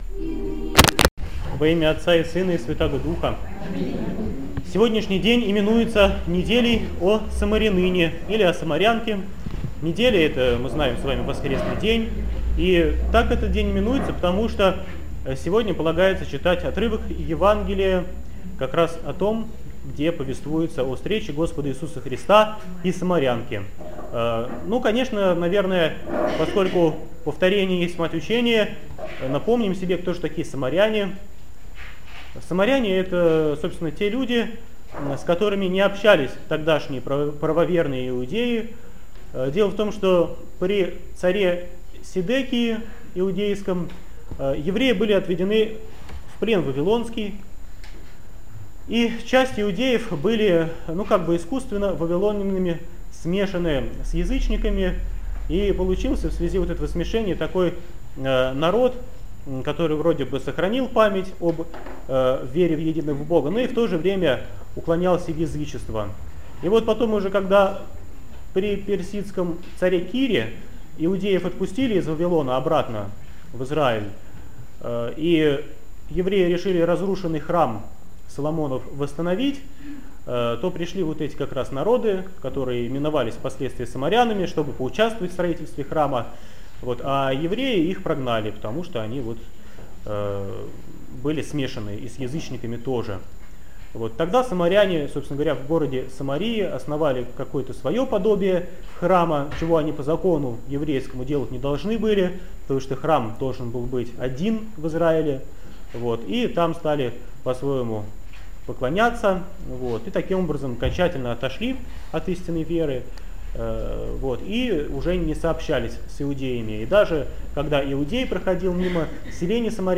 Проповедь в Неделю о самарянке 2013